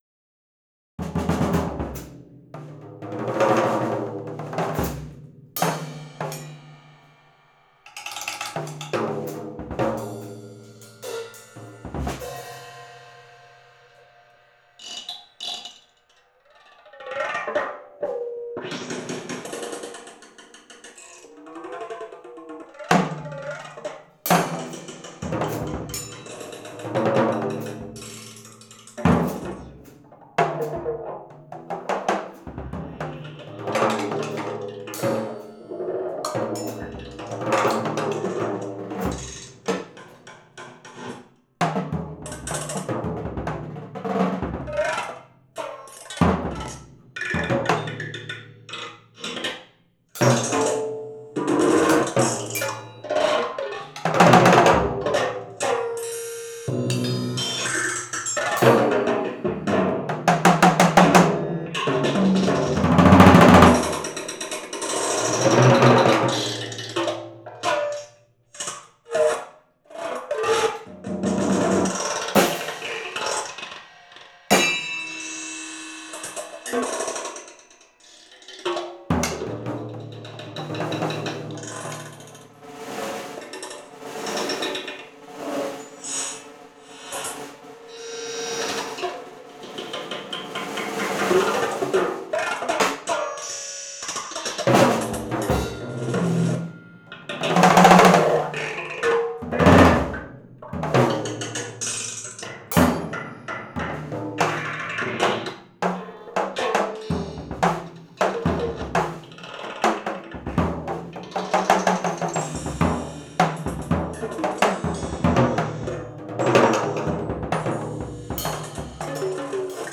Augmenting Percussion with Electronics in Improvised Music Performance
Keywords: Improvised music, improvisation, live electronics, digital electronics, analogue electronics, hybrid instrument, electroacoustic music, solo percussion, Human Computer Interaction